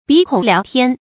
鼻孔辽天 bí kǒng liáo tiān
鼻孔辽天发音